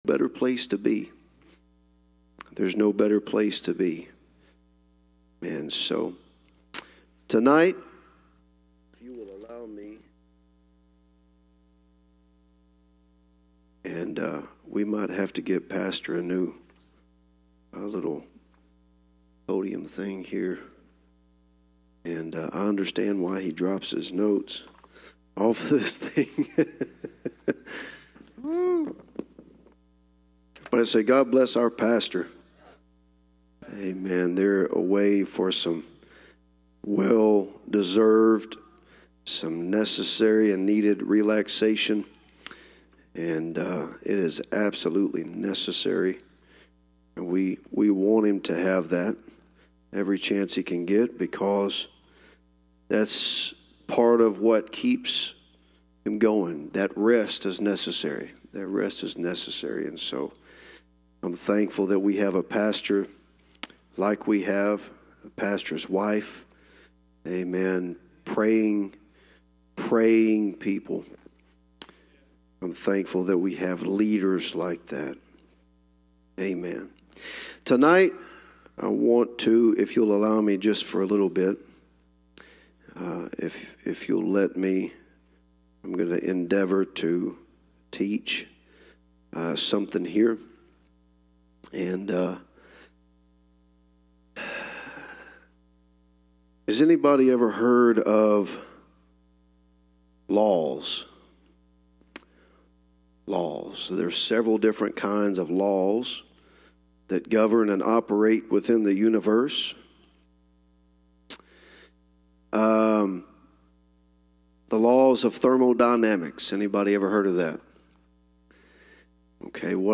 UPC Section Youth Rally